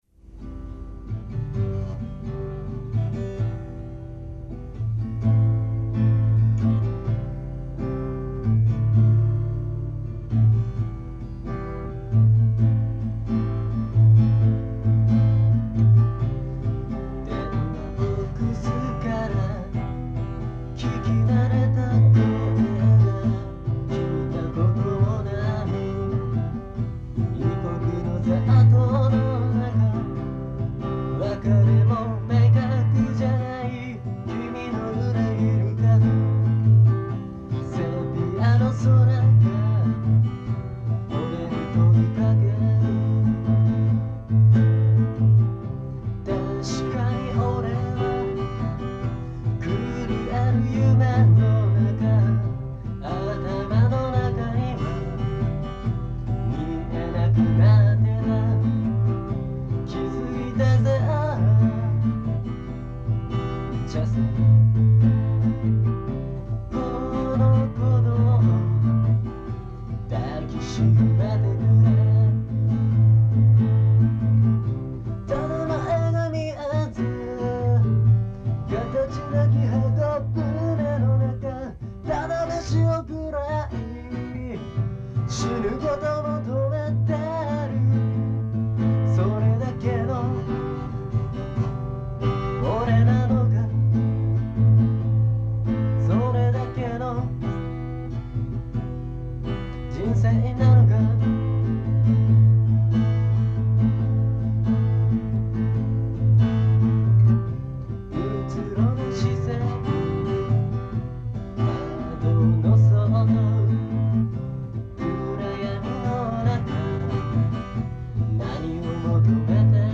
曲の説明 この曲はフォークとロックを融合したものです。